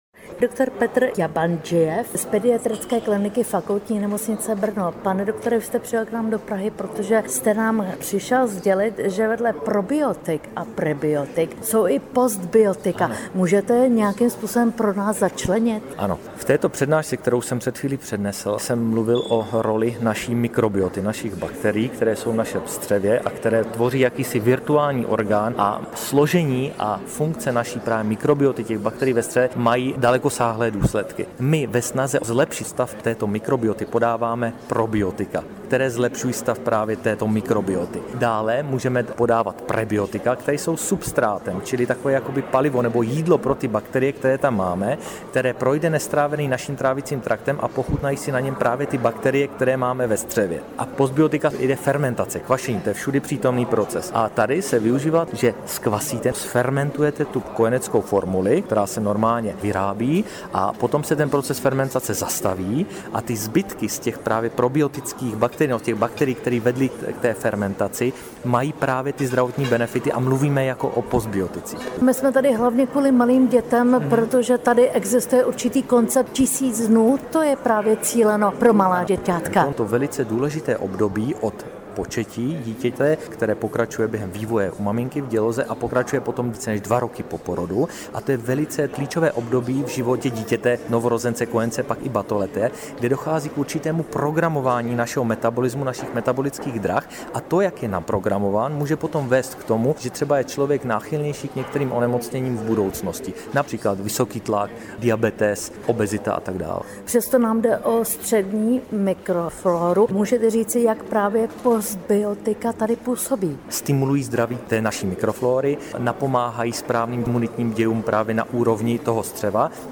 postprobiotika-pro-miminka-pediatr.mp3